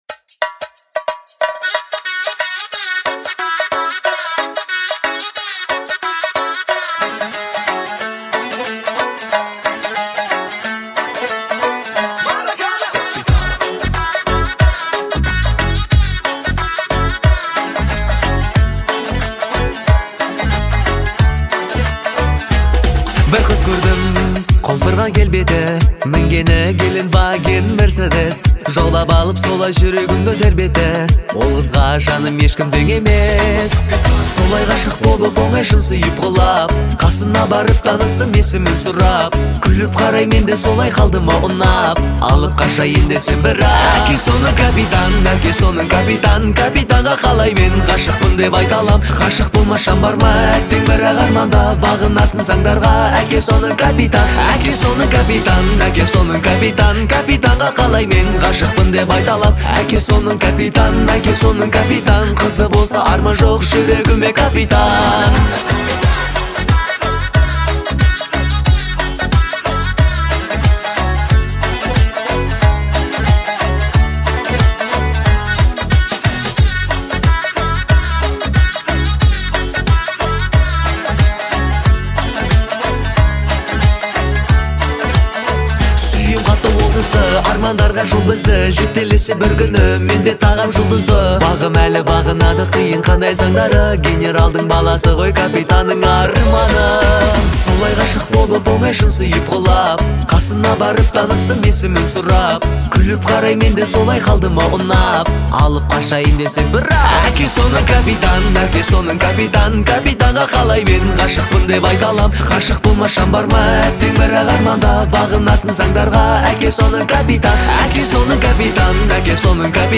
это трогательная композиция в жанре казахского поп-фольклора